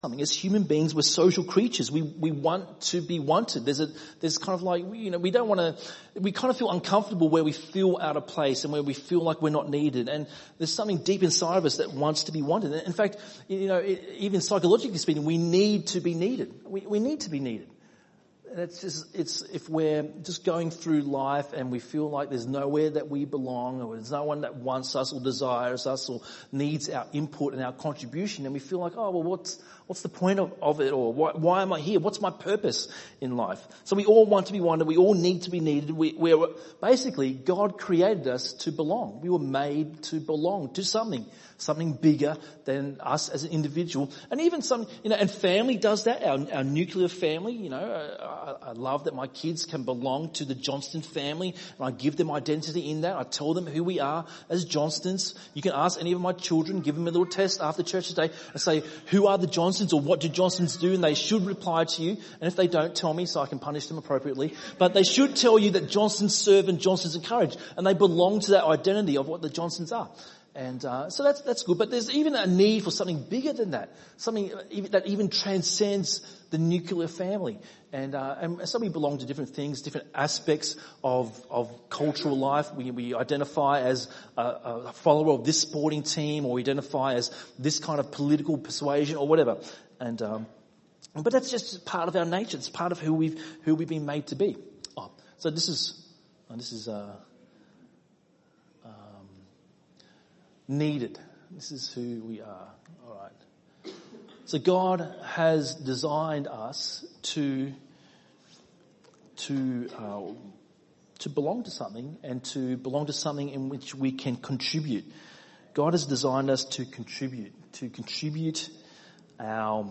by enmelbourne | Jun 25, 2019 | ENM Sermon